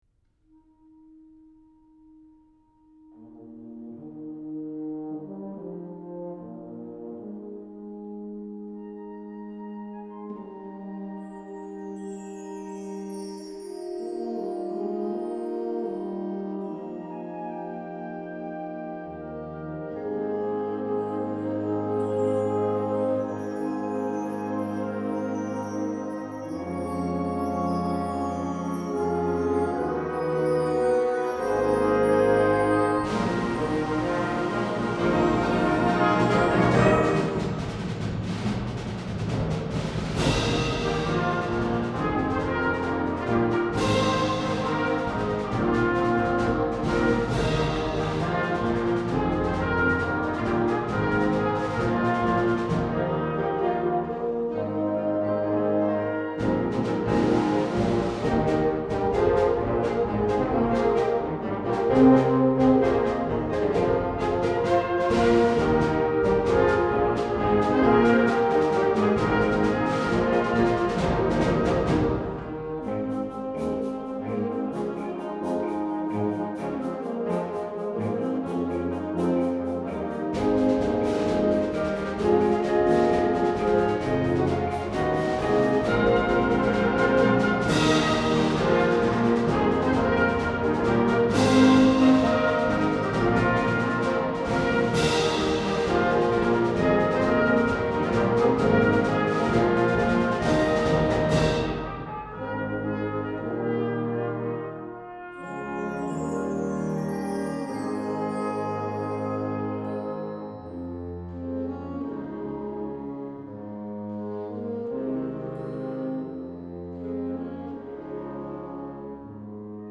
Genre: Band
Percussion 1 & 2: Snare Drum, Bass Drum
Percussion 5 & 6: Triangle, Cabasa, Claves